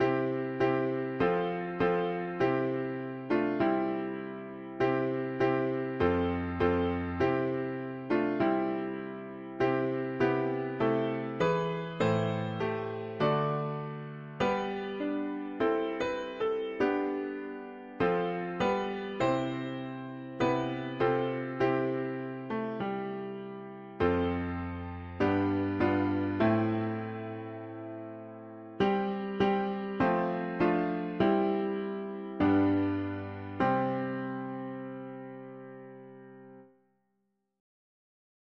We’ll walk hand in hand … english secular 4part chords
African-American Spiritual
Key: C major Meter: irregular